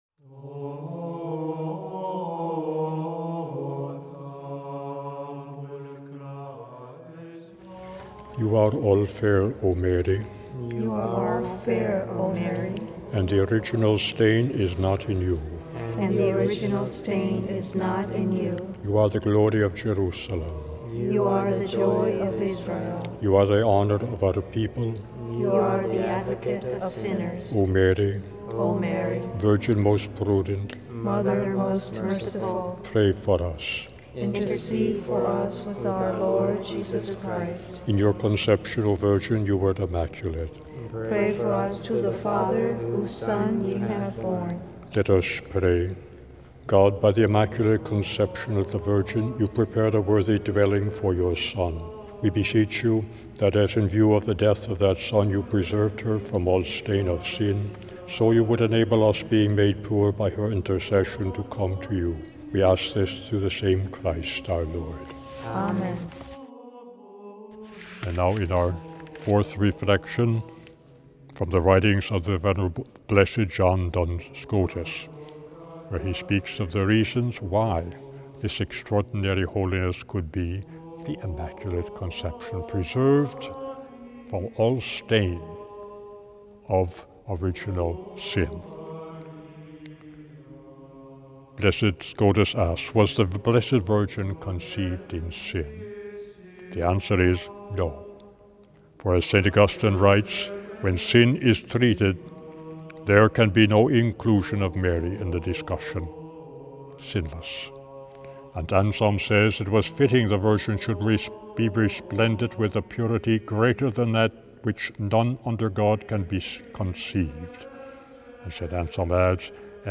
Throughout these 9 days you'll hear beautiful meditations on Our Lady taken from the writings of St. Francis, St. Bernadine of Siena, St. Joseph of Cupertino, and more.